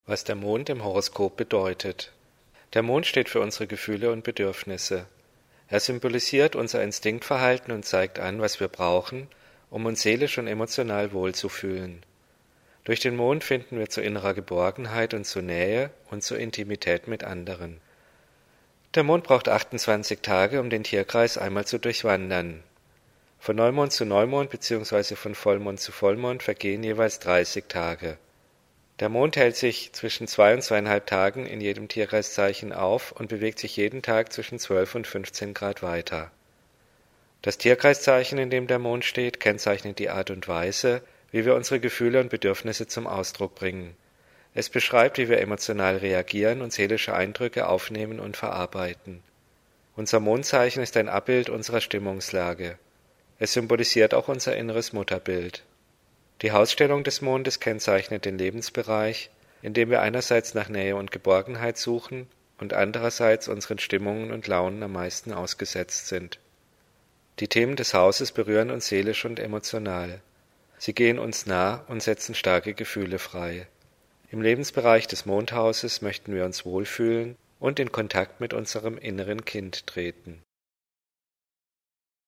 Lehr-Hörbuch Grundwissen